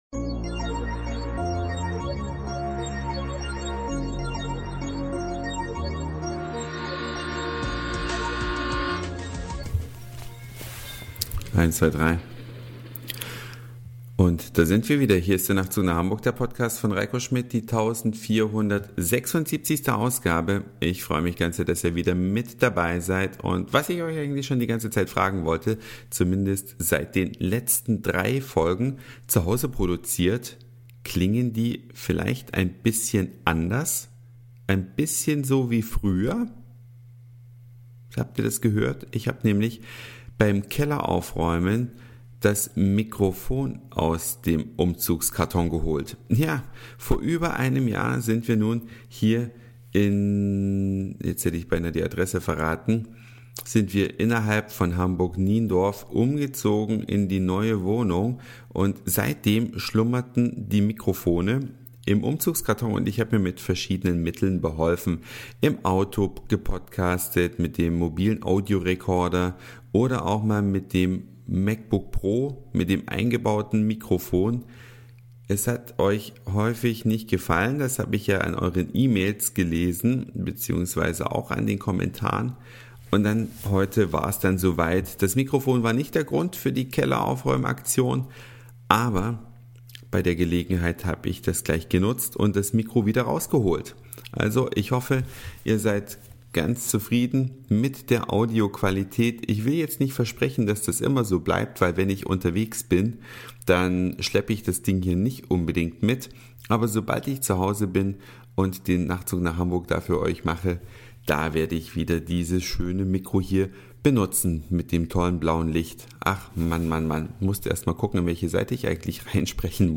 Das "alte" Mikrofon ist wieder aus dem Umzugskarton auf den